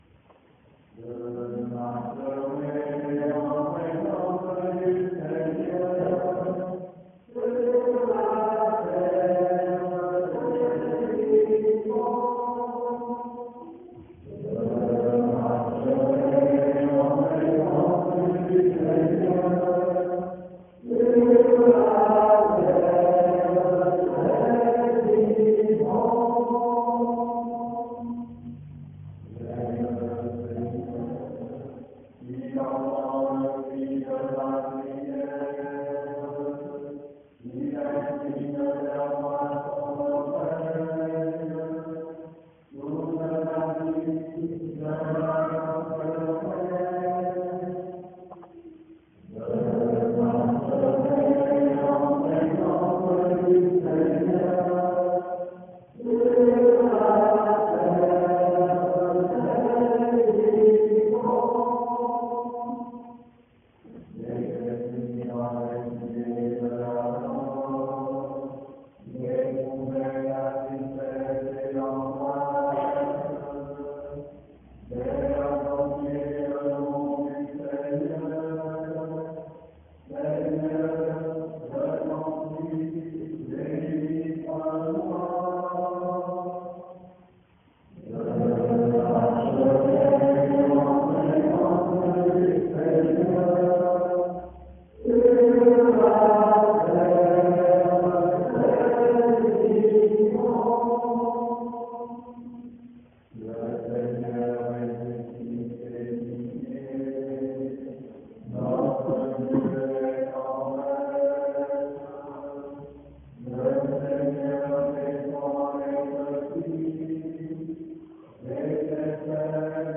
Sons enregistrés lors de la cérémonie :
chant (je marcherai en présence du Seigneur)